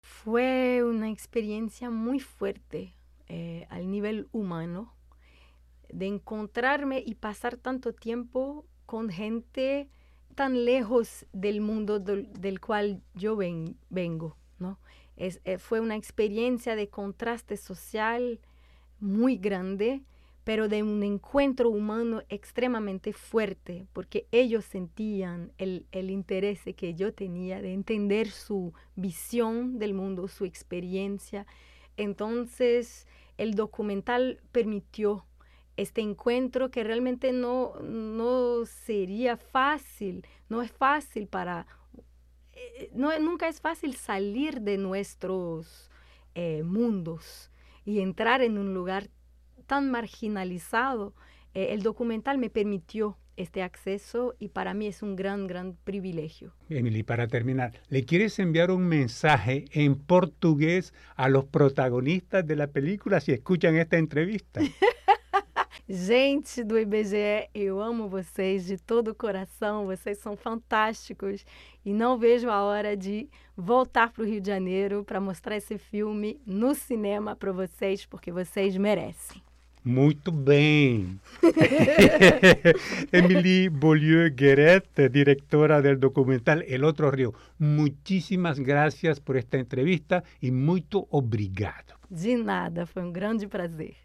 Al momento de hacer esta entrevista ya la película estaba siendo presentada en salas.